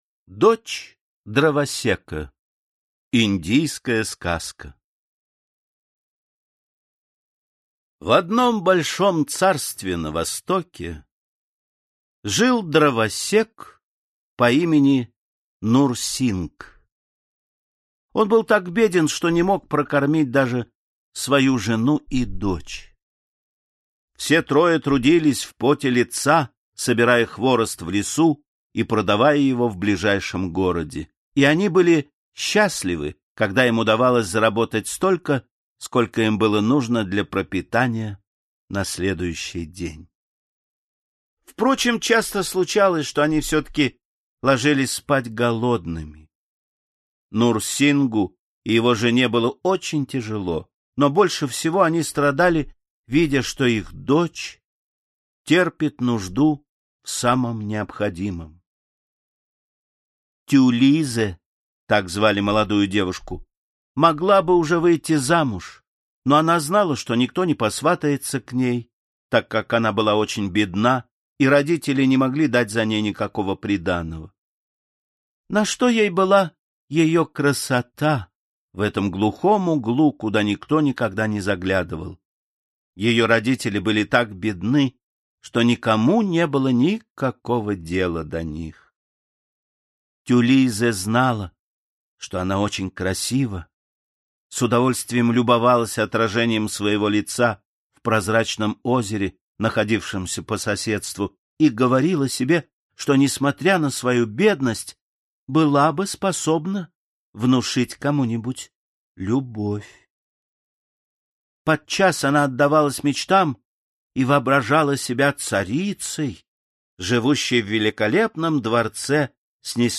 Аудиокнига Золотая книга сказок. Индийские сказки | Библиотека аудиокниг